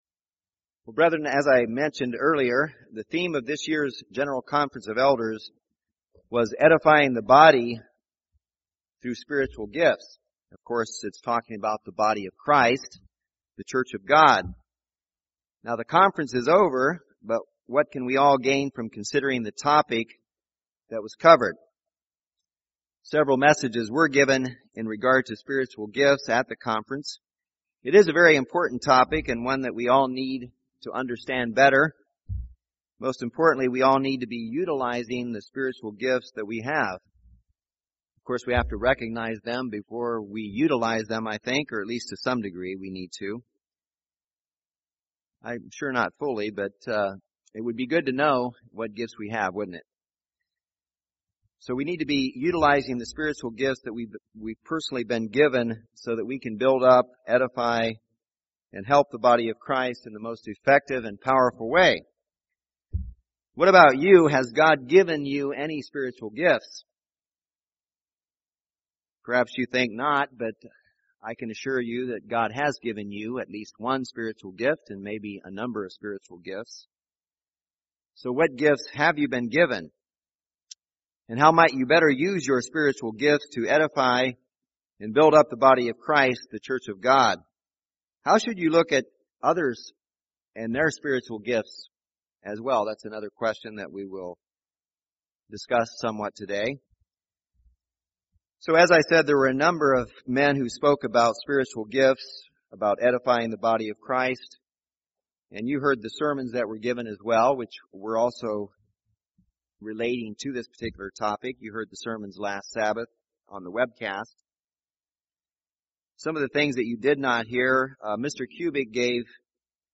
This sermon discusses some of the things said regarding spiritual gifts at the conference and was given to inspire our brethren to consider their own spiritual gifts and how to use them for the benefit of God’s Church in their own local congregation.